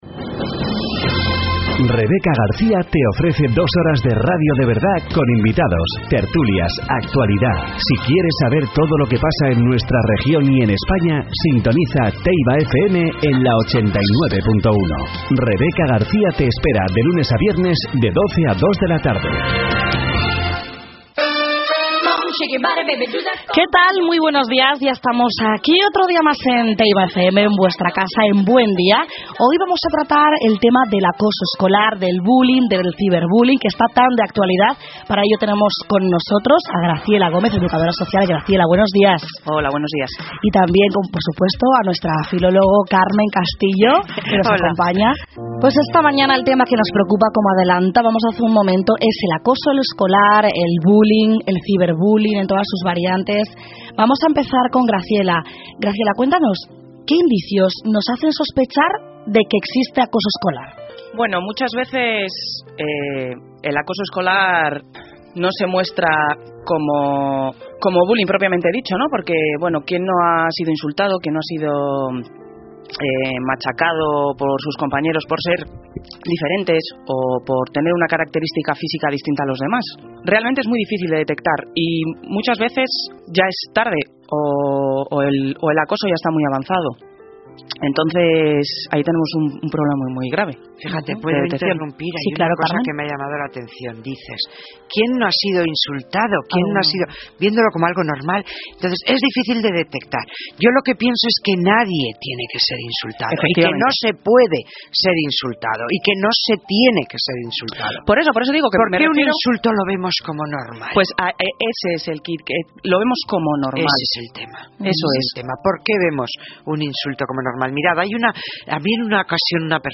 Profesionales de Fundipp hablan sobre Acoso Escolar en TeibaFm